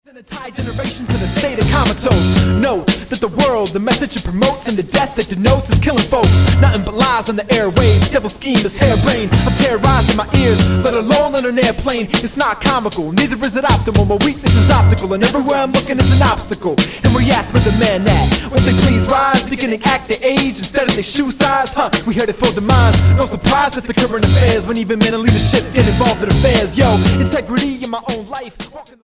rapper
Style: Hip-Hop